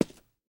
immersive-sounds / sound / footsteps / rails / rails-08.ogg
rails-08.ogg